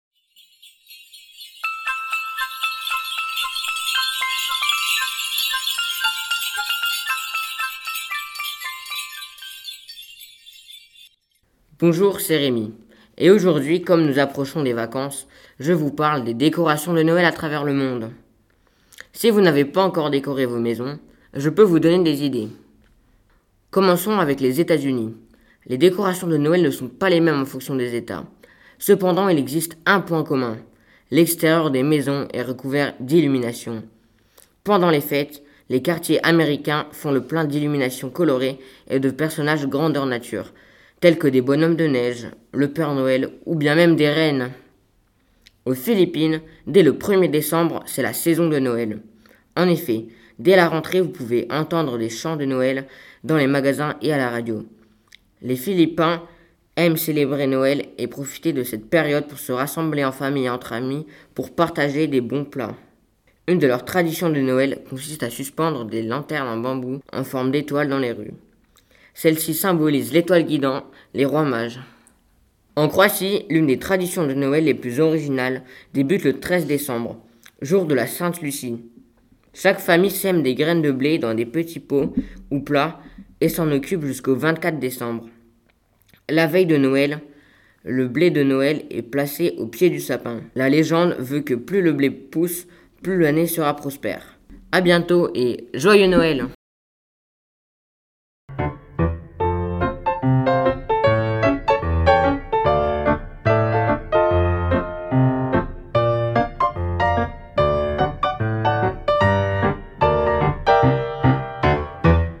Archives de catégorie : Reportages
Jingle : Bruitages : UniversalSoundbank.